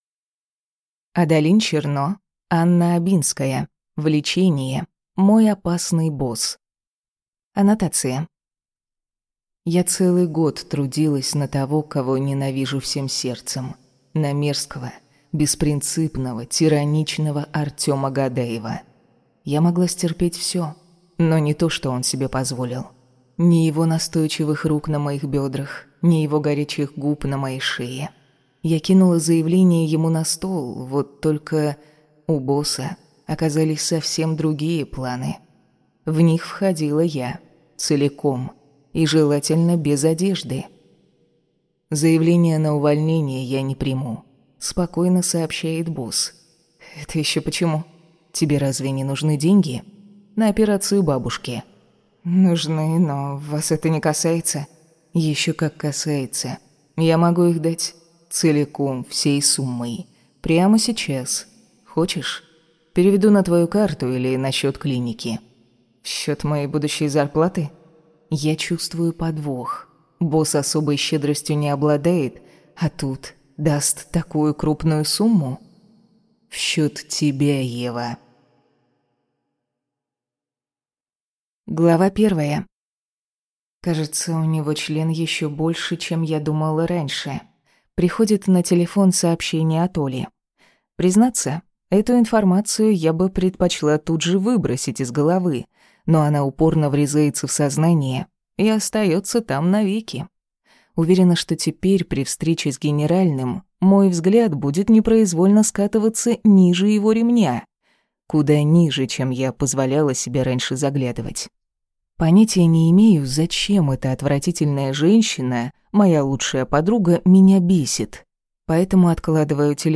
Аудиокнига Влечение. Мой опасный босс | Библиотека аудиокниг